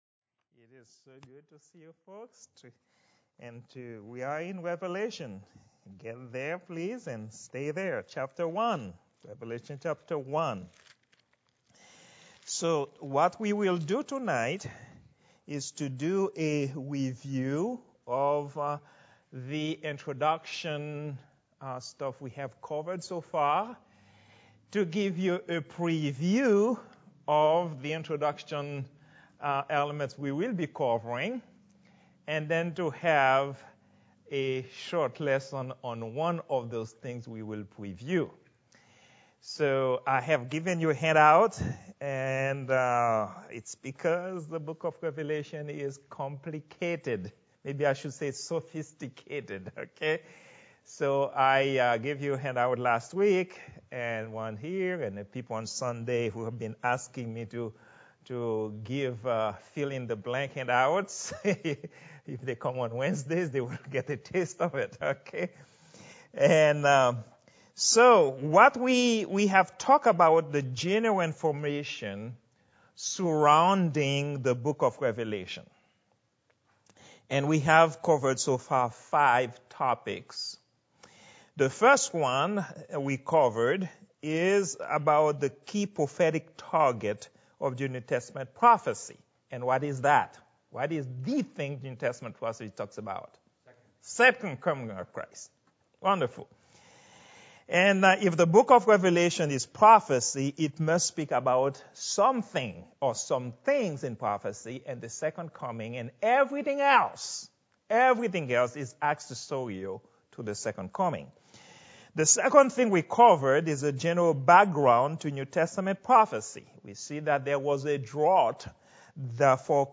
Prayer_meeting_04_27_2022.mp3